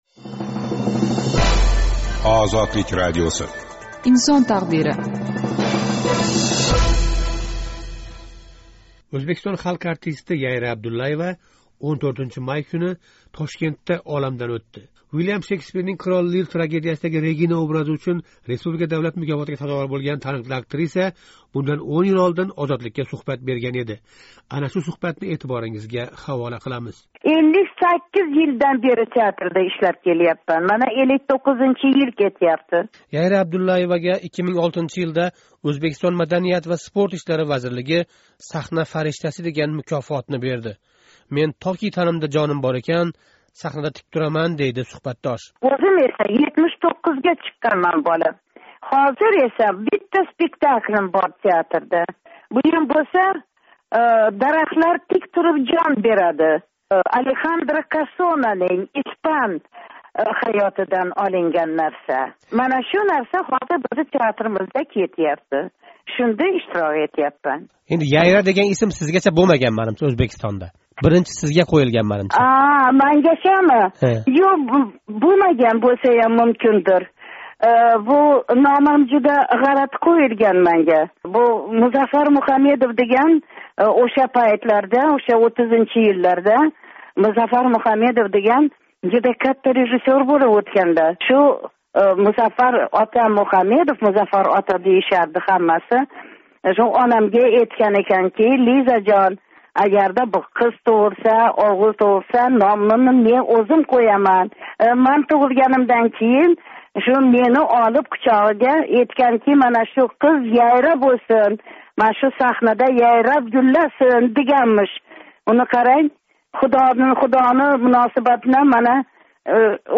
Яйра Абдуллаева билан суҳбат